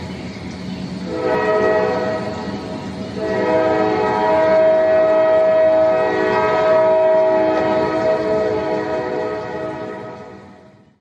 train 2